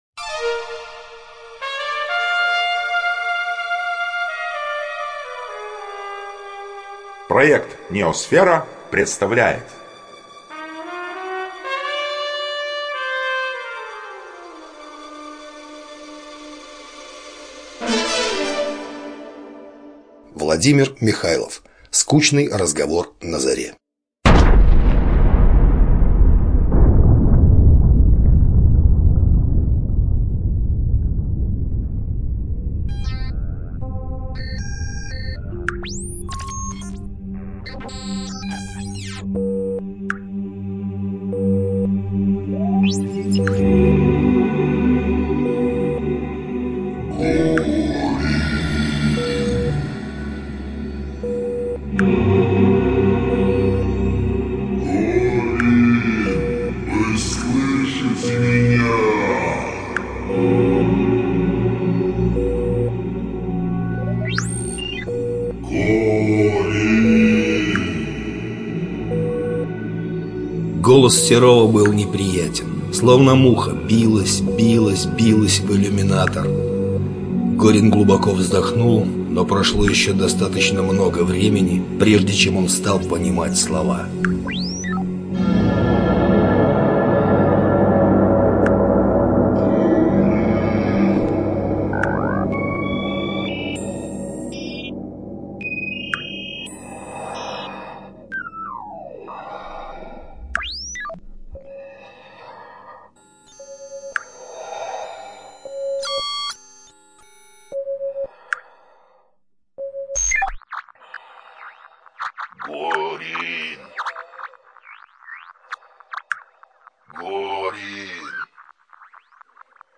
Озвучивает: NEOСФЕРА